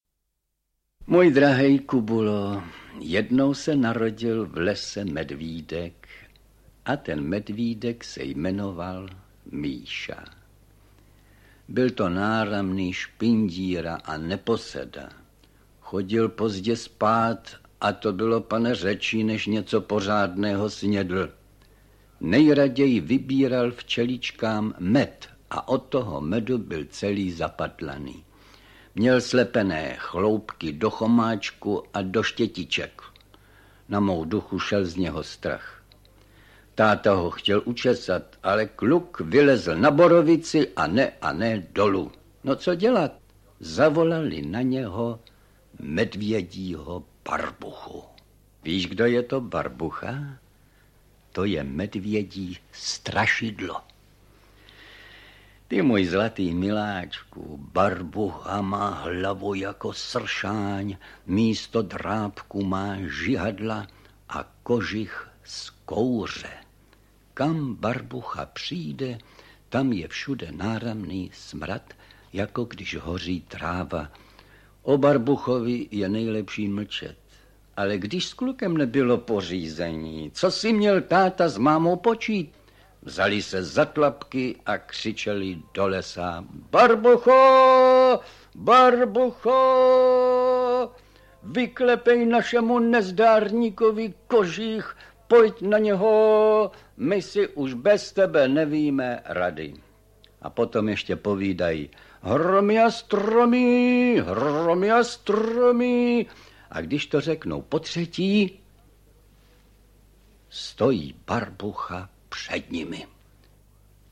Kubula a Kuba Kubikula audiokniha
Ukázka z knihy
• InterpretFrantišek Smolík